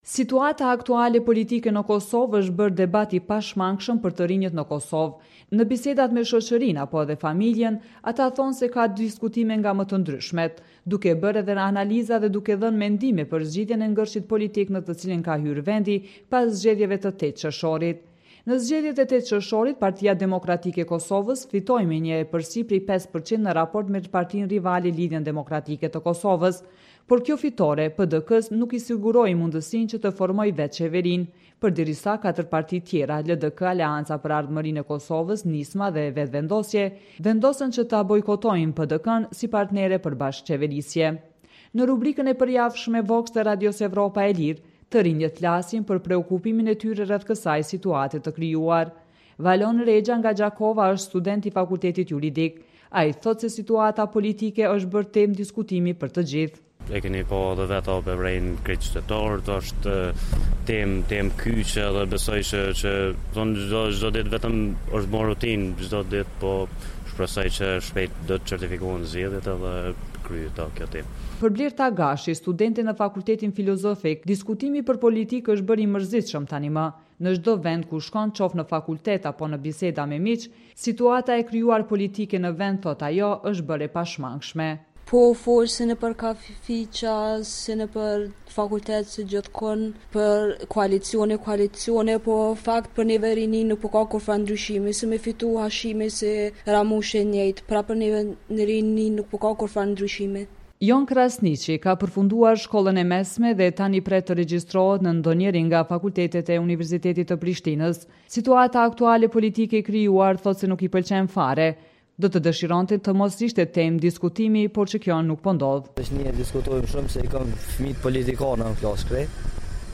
VOX: “Magjepsja” me politikën